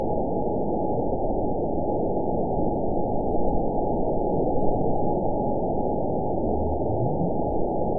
event 912342 date 03/24/22 time 22:31:30 GMT (3 years, 1 month ago) score 9.45 location TSS-AB01 detected by nrw target species NRW annotations +NRW Spectrogram: Frequency (kHz) vs. Time (s) audio not available .wav